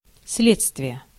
Ääntäminen
IPA: /ˈslʲet͡stvʲɪjə/